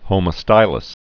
(hōmō-stīləs)